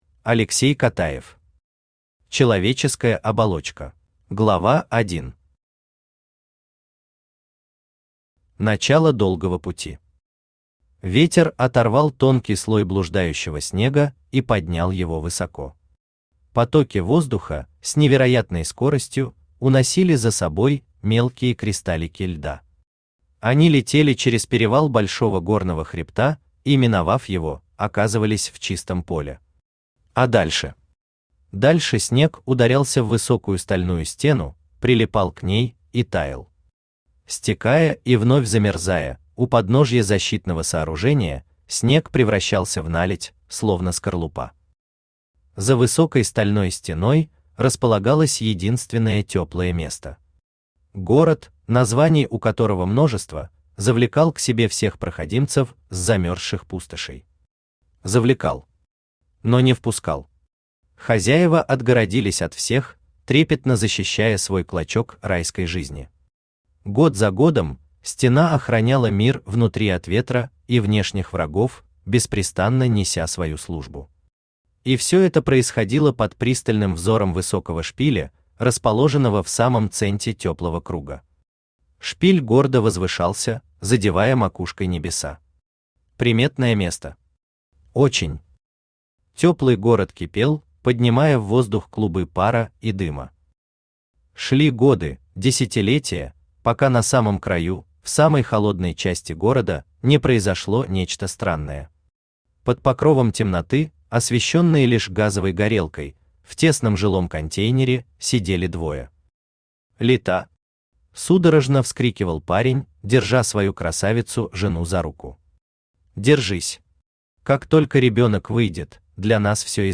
Аудиокнига Человеческая оболочка | Библиотека аудиокниг
Aудиокнига Человеческая оболочка Автор Алексей Котаев Читает аудиокнигу Авточтец ЛитРес.